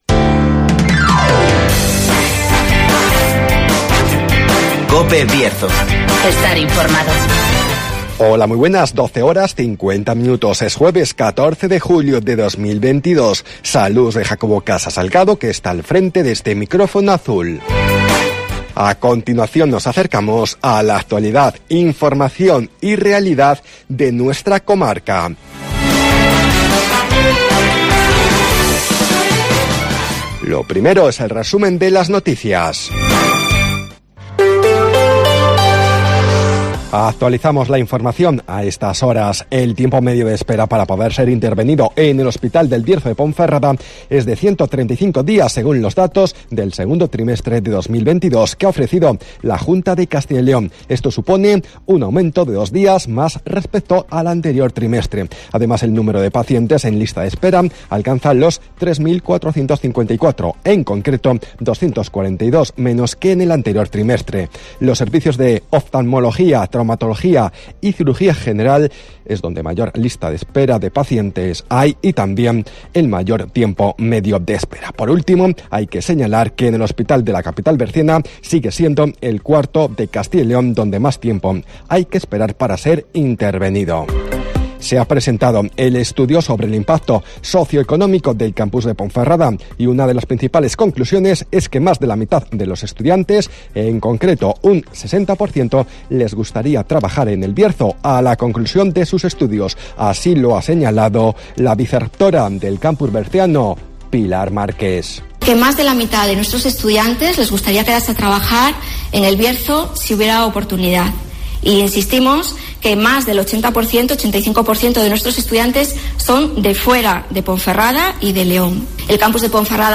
AUDIO: Resumen de las noticas, El Tiempo y Agenda